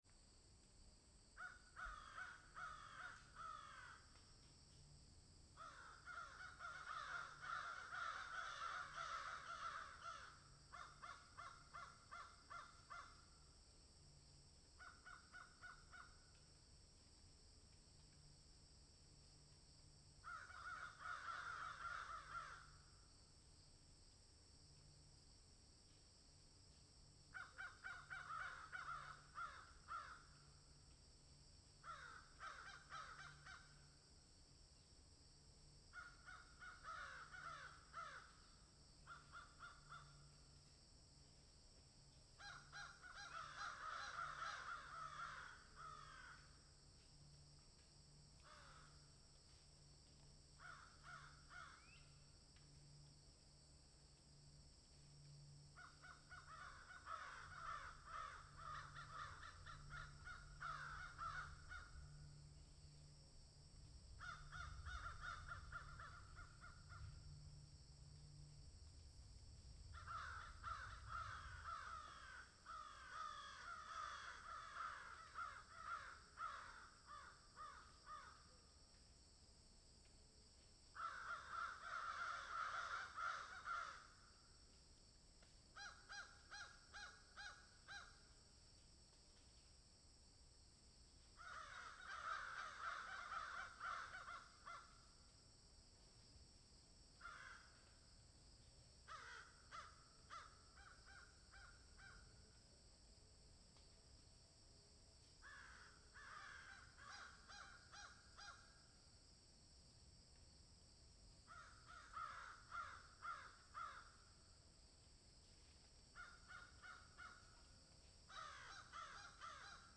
Ambient_CrowsOnField.wav